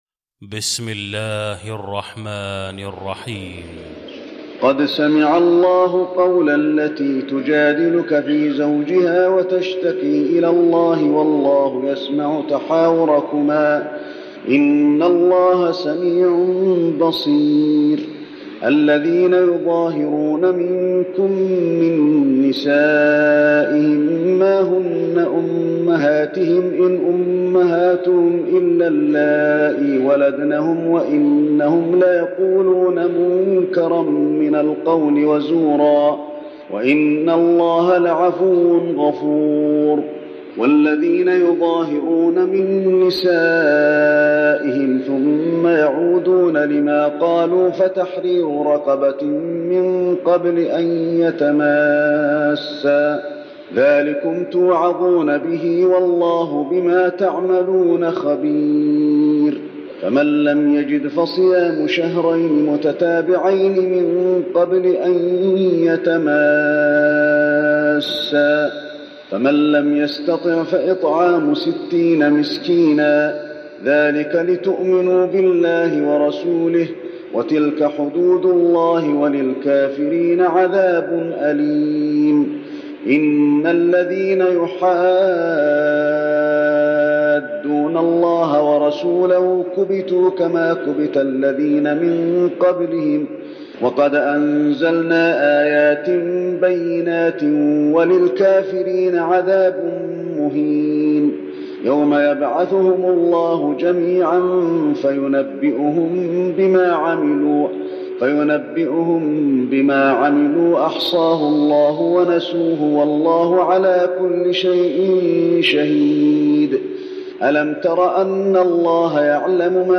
المكان: المسجد النبوي المجادلة